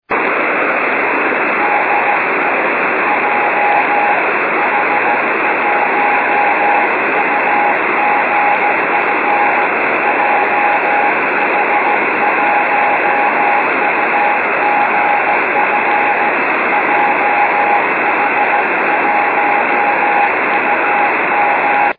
ANT方向: 六本木ヒルズ
伝播距離：61km